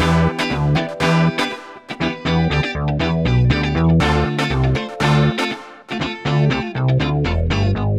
29 Backing PT2.wav